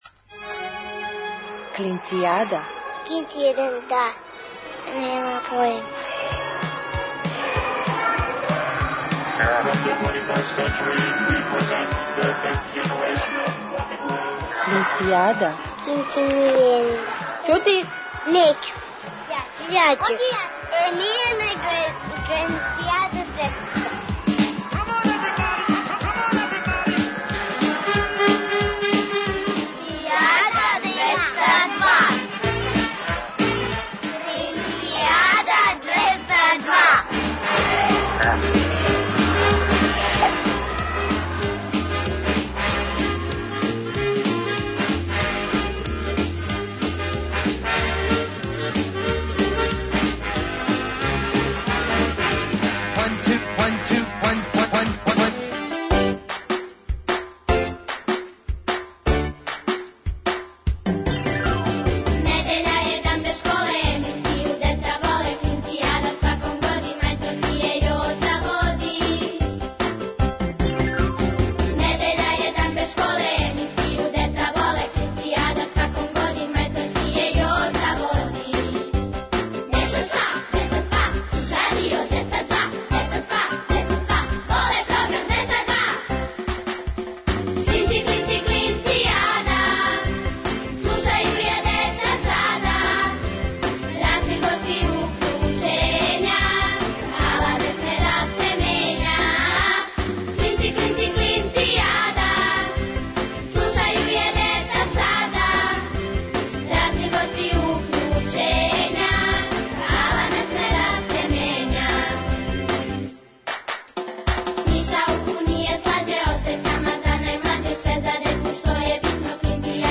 Сваке недеље уживајте у великим причама малих људи, бајкама, дечјим песмицама.